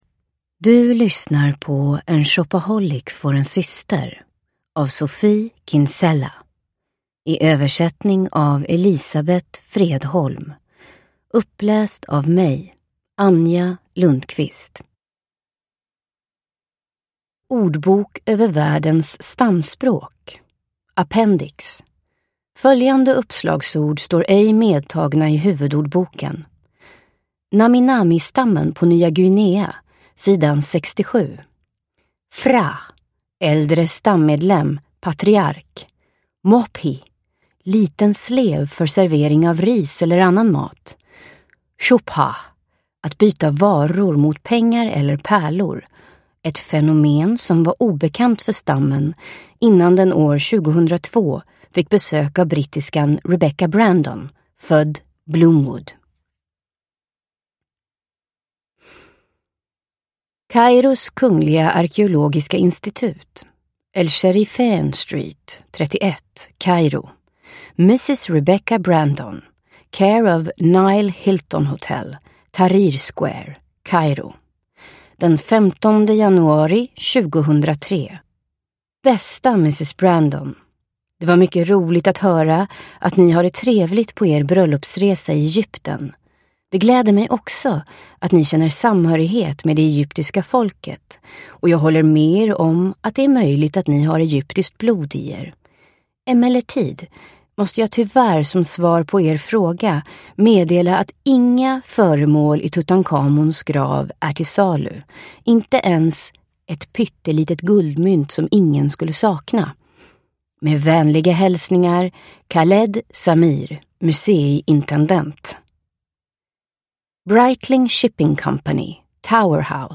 En shopaholic får en syster – Ljudbok – Laddas ner